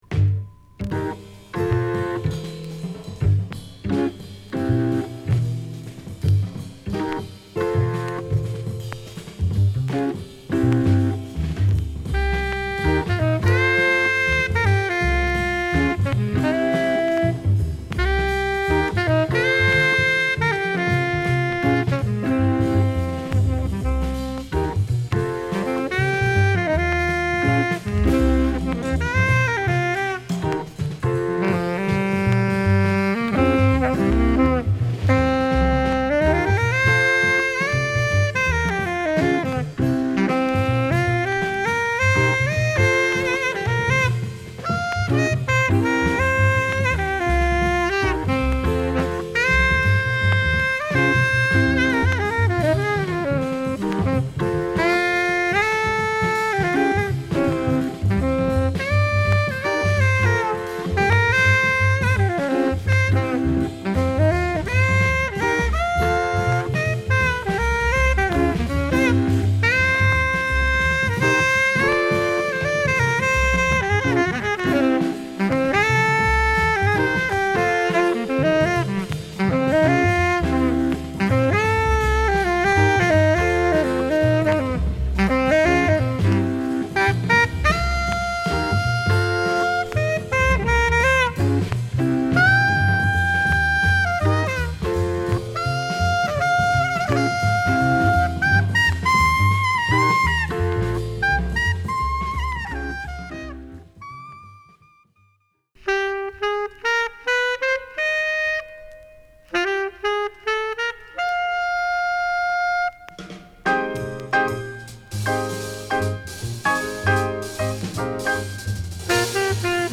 Mono
＊SideA大きい傷有りチリパチ出ます。